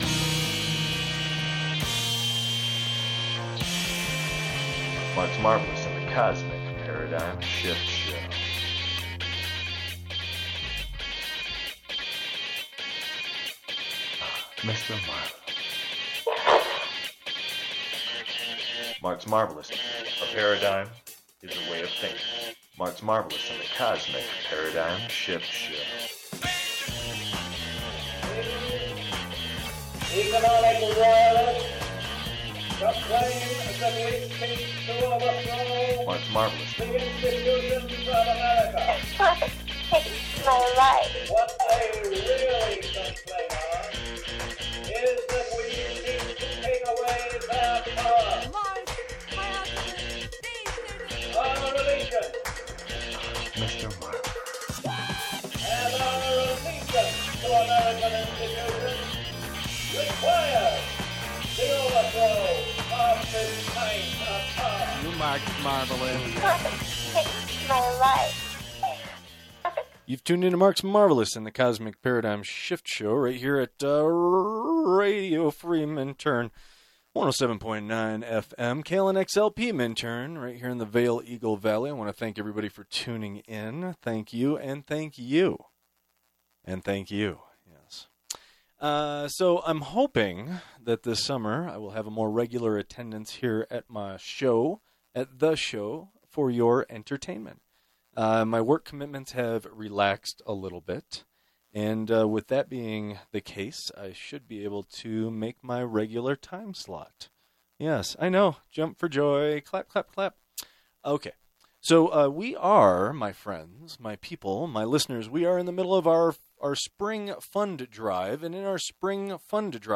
Broadcasting live most Friday nights 2:00 pm -4:00pm Mountain time at Radio Free Minturn - KLNX FM 107.9